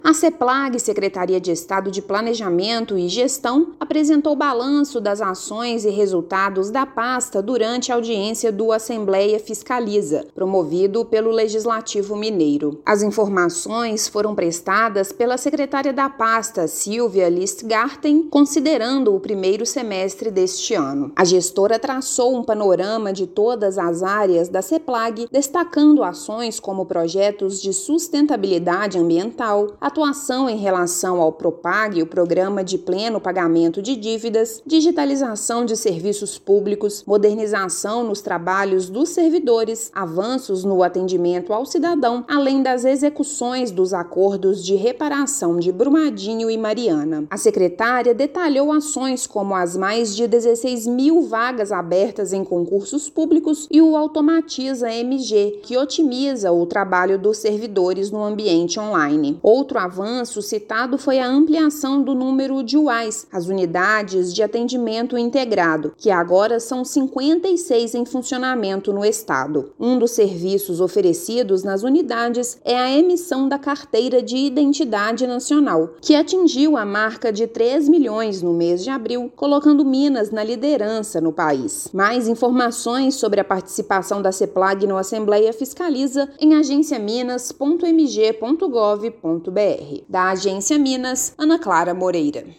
[RÁDIO] Avanços no atendimento ao cidadão, serviços de trânsito e acordos de reparação são destaques da Seplag no Assembleia Fiscaliza
Levantamento de ações do primeiro semestre da Secretaria, incluindo a atuação sobre o Propag, foram apresentadas na ALMG. Ouça matéria de rádio.